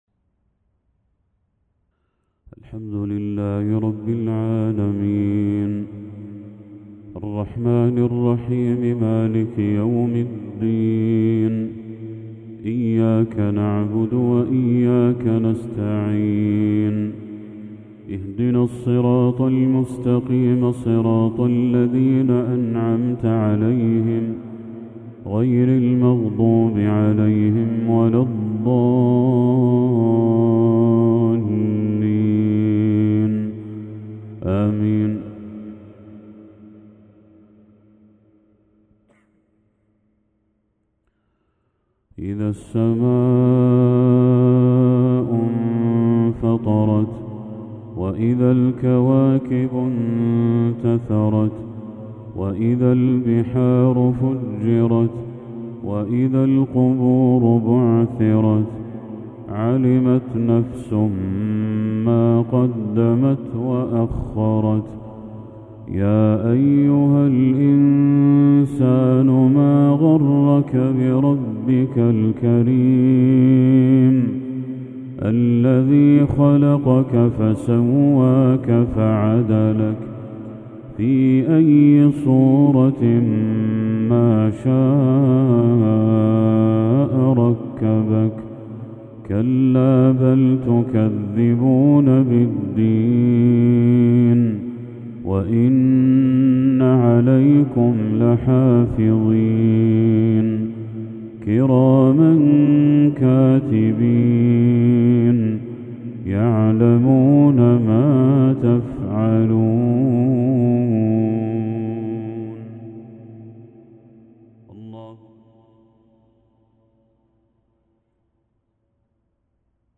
تلاوة للشيخ بدر التركي سورة الانفطار كاملة | مغرب 18 ذو الحجة 1445هـ > 1445هـ > تلاوات الشيخ بدر التركي > المزيد - تلاوات الحرمين